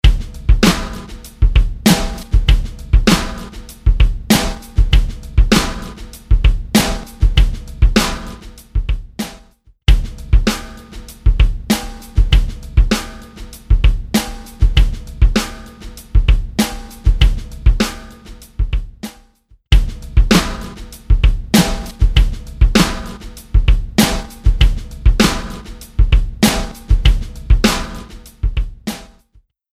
H910 Harmonizer | Snare Drum | Preset: You Shook the Snare
ピッチ、ディレイ、モジュレーションを備えた世界初のマルチエフェクト・プロセッサー
H910-Harmonizer-Eventide-Snare-You-Shook-The-Snare.mp3